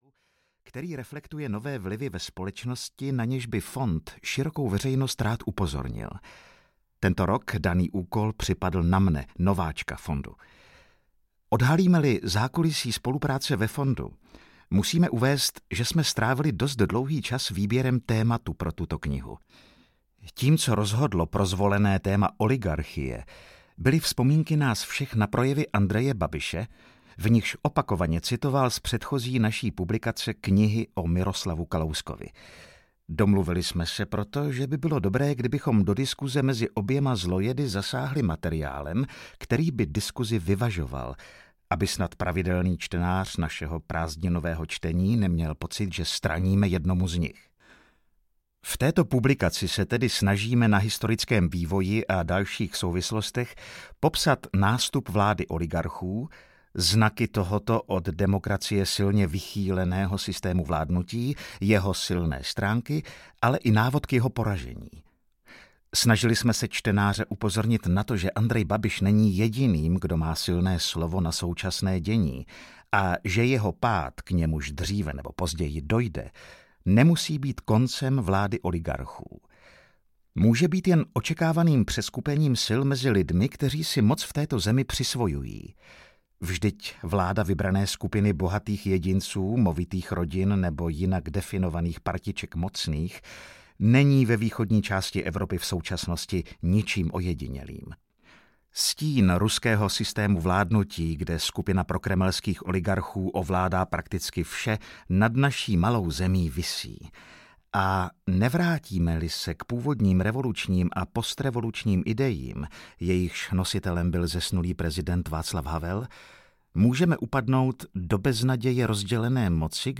Audio knihaČas oligarchů, jejich sluhů a nepřátel
Ukázka z knihy
• InterpretAleš Procházka